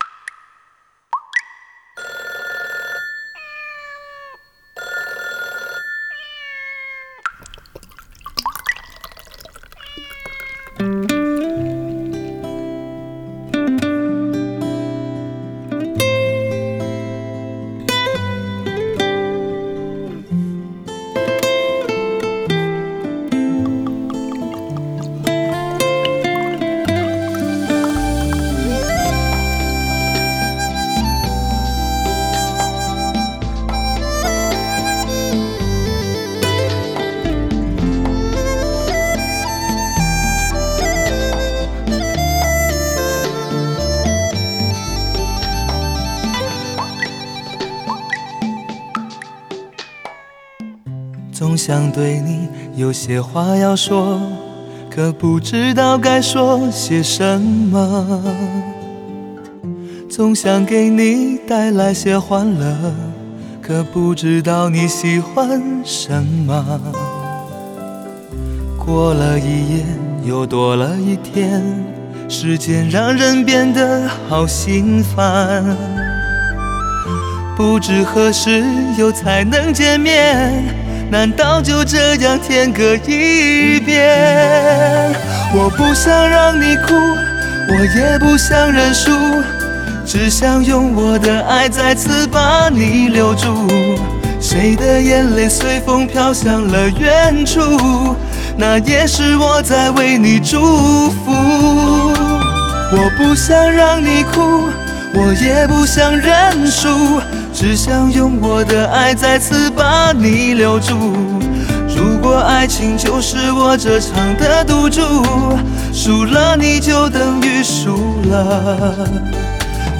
Ps：在线试听为压缩音质节选，体验无损音质请下载完整版
前奏较长 请等待